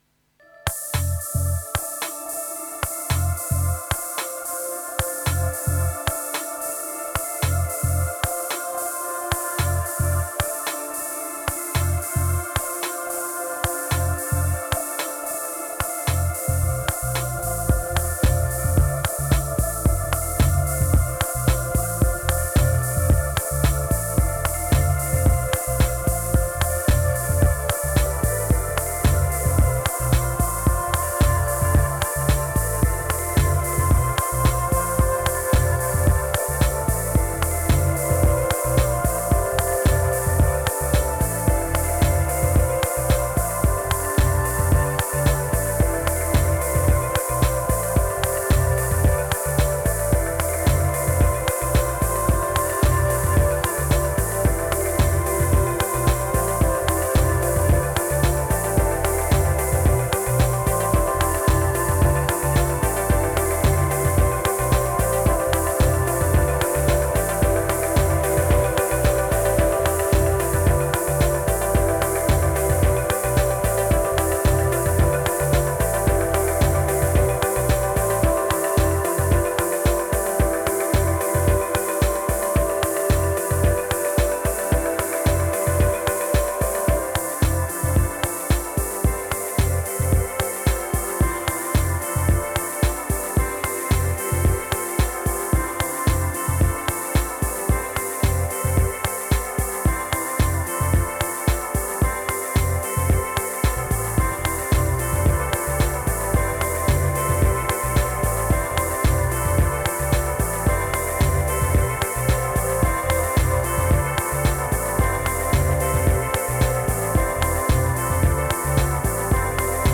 138📈 - 94%🤔 - 111BPM🔊 - 2025-10-18📅 - 291🌟
Epic Stellar Afterwards Blessings Binaries Bold Bright Crush